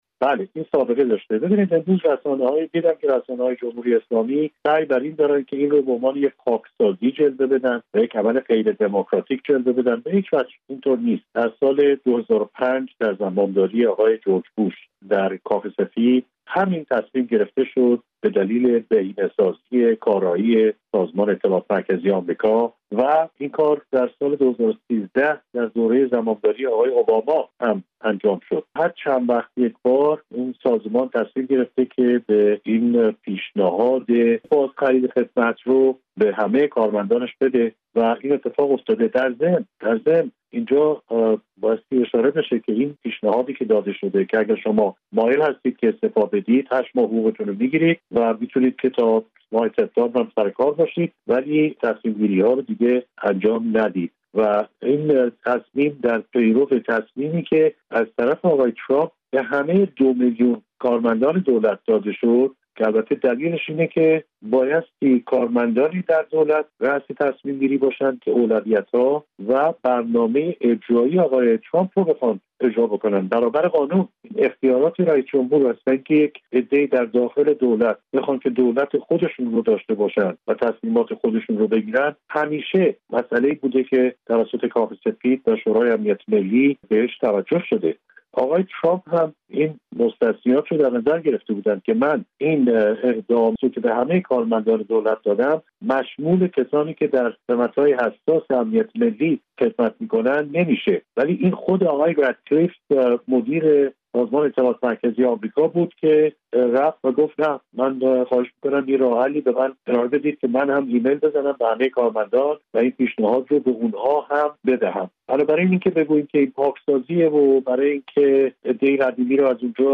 سابقهٔ بازخرید خدمت در «سی‌آی‌ای» در گفت‌وگو با عضو ایرانی حزب‌ جمهوریخواه